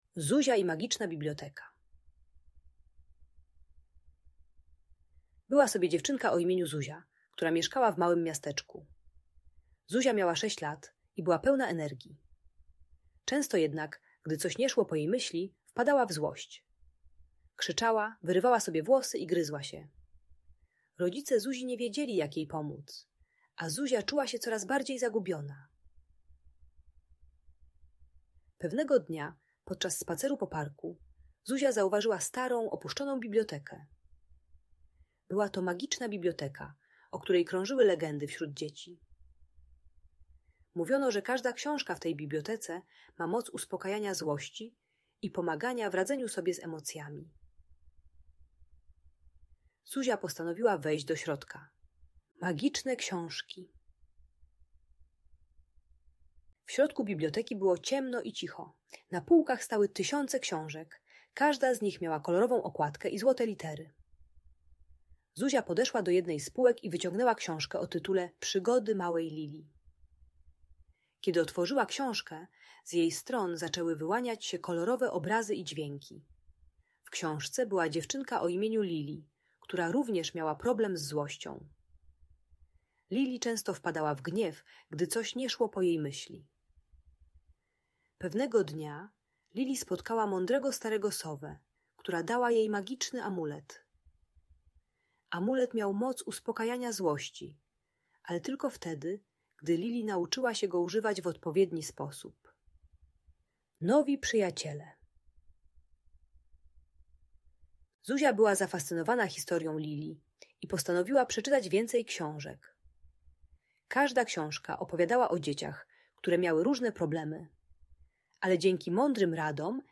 Zuzia i Magiczna Biblioteka - Audiobajka